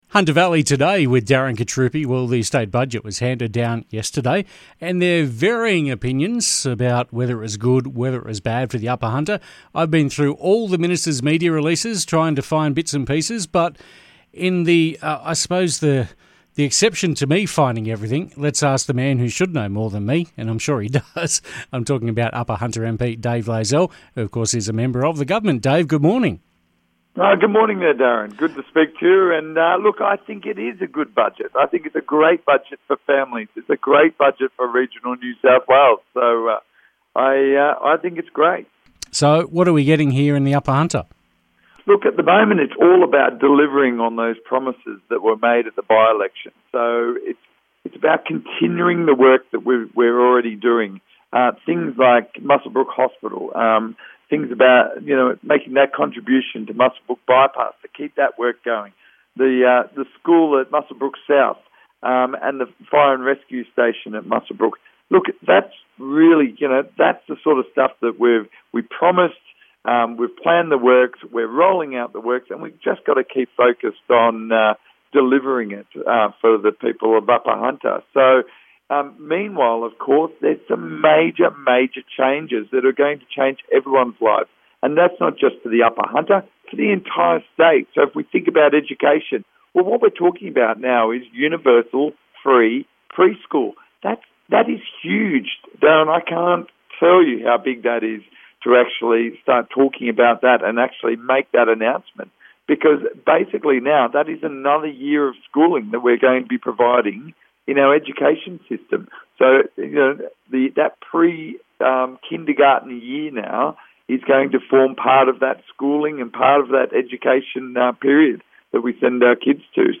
Upper Hunter MP Dave Layzell on the 2022 State Budget